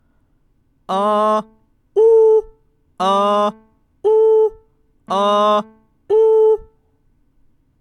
１. まずは地声／裏声を分けた状態で安定して発声できるか
音量注意！